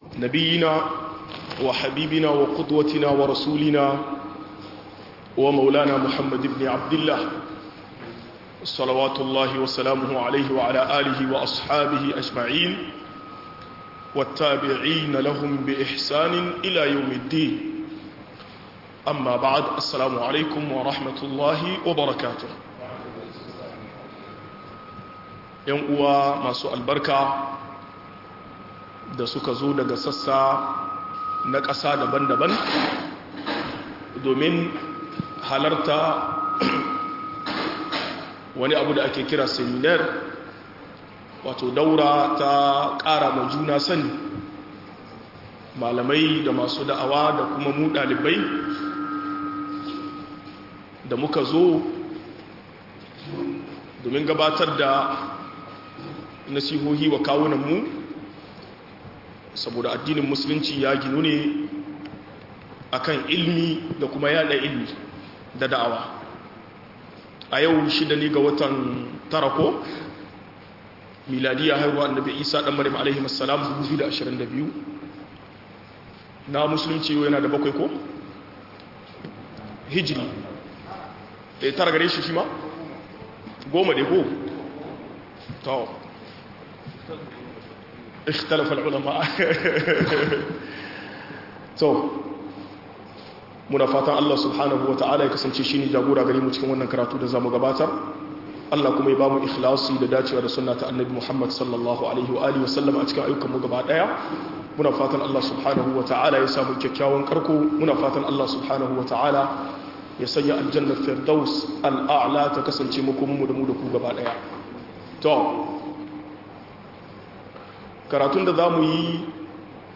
Musan kanmu musan makiyanmu - MUHADARA